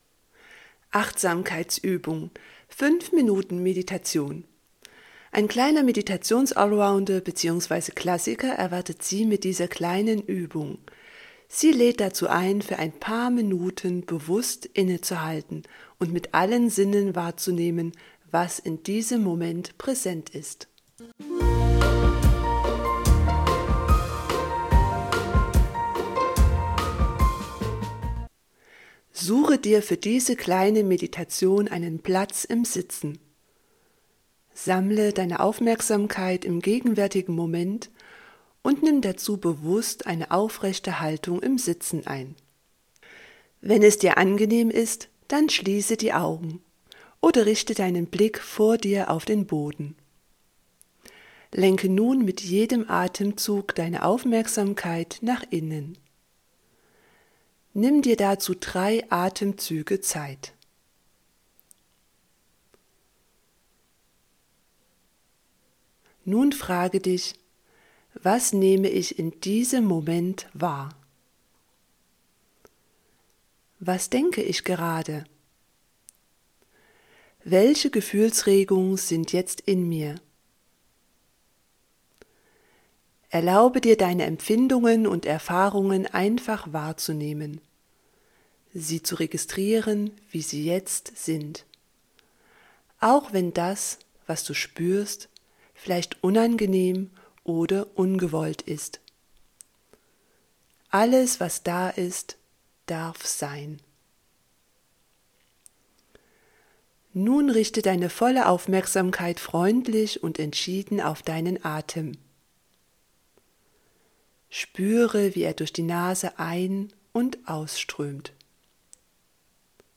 5 Minuten Meditation